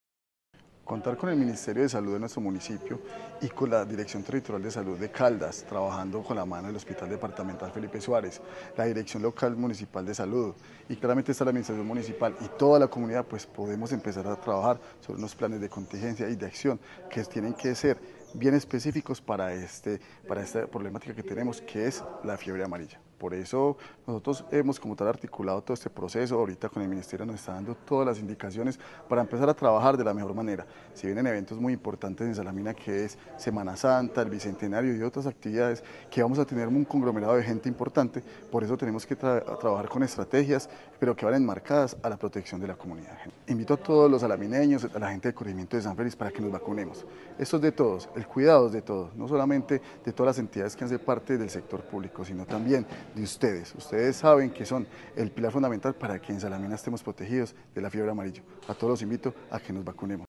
Manuel Fermín Giraldo Gutiérrez, alcalde de Salamina